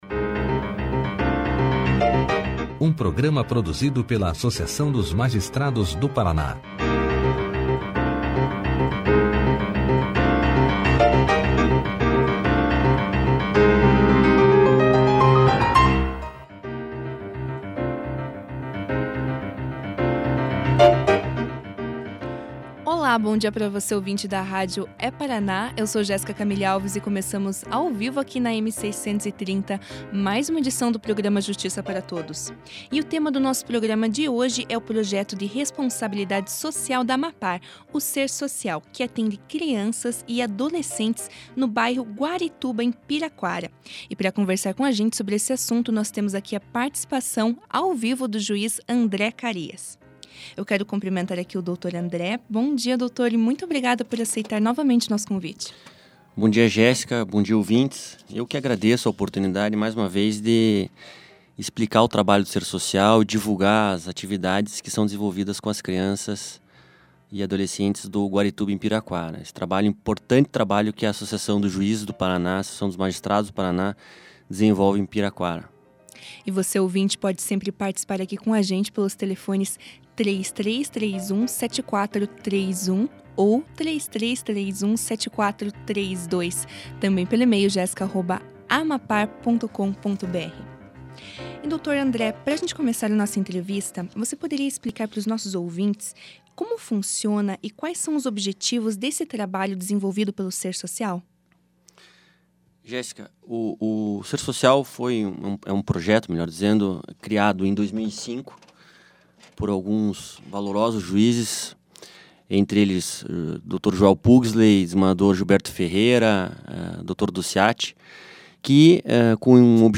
Clique aqui e ouça a entrevista do juiz André Carias sobre o Projeto Ser Social na íntegra.